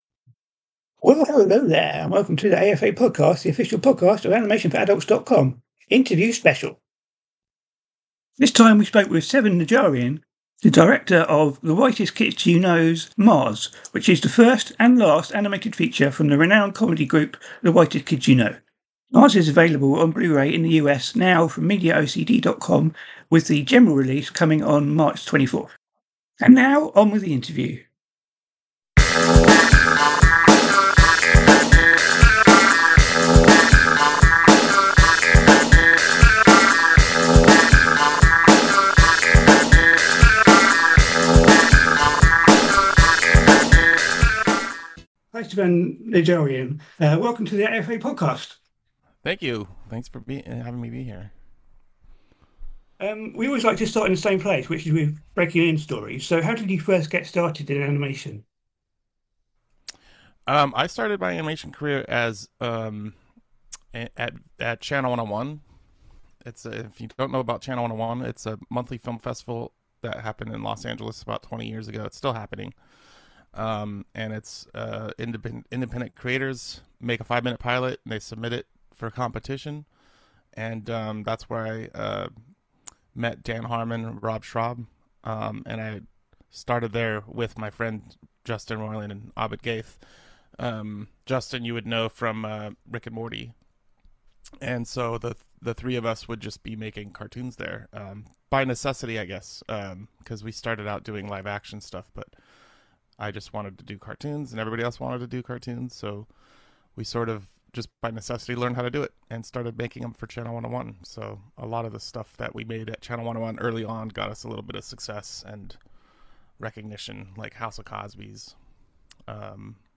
The AFA Podcast Interview
Interview with director and animator